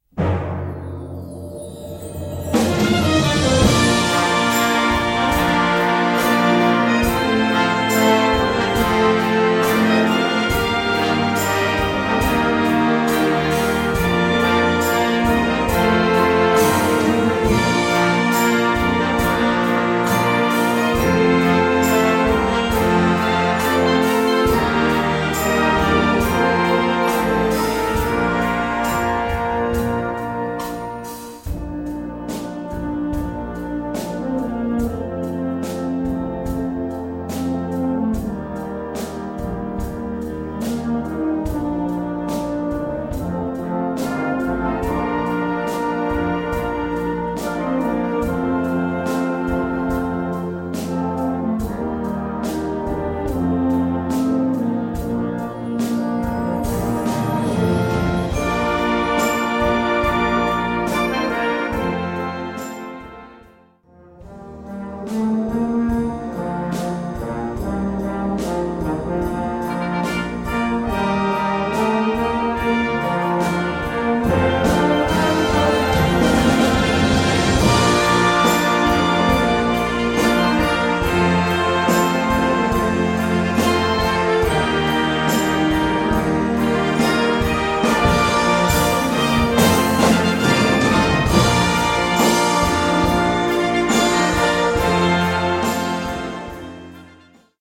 Gattung: Choräle, Balladen, lyrische Musik
Besetzung: Blasorchester